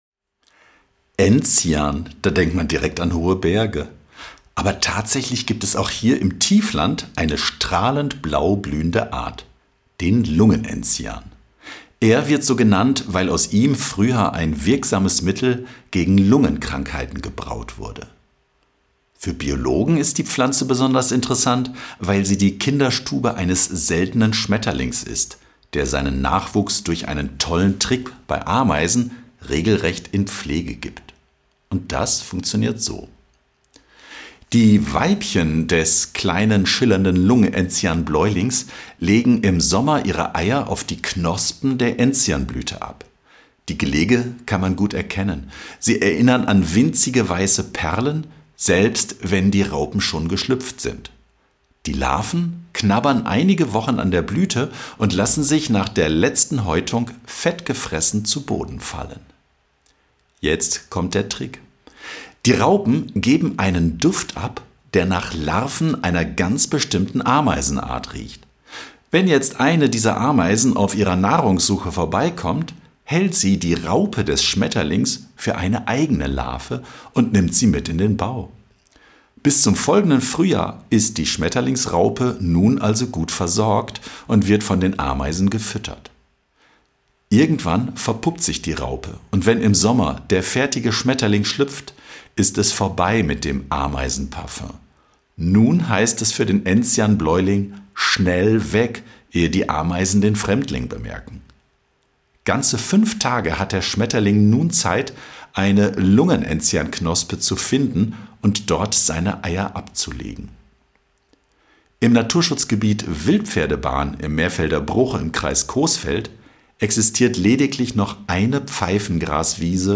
→ Hörspiel: Lungen-Enzian, Schmetterling und Ameise